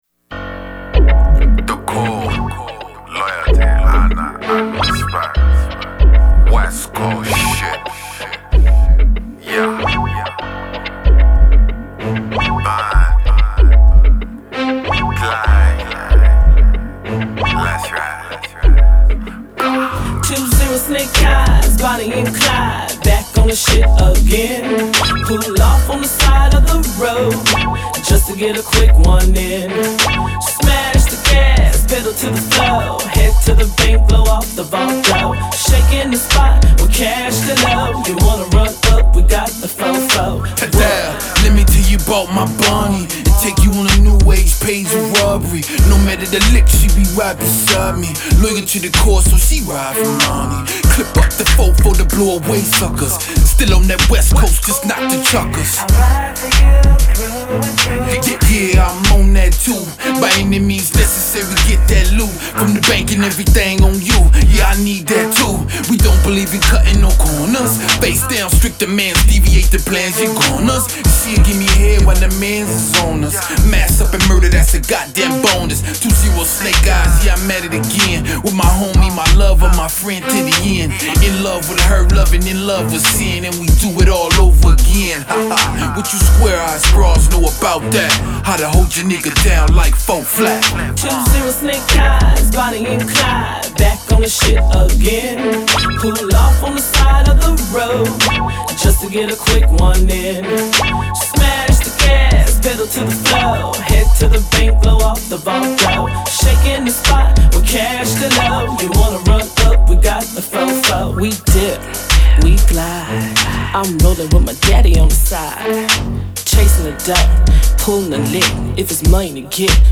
Hiphop
Description : Bonnie & Clyde Westcoast style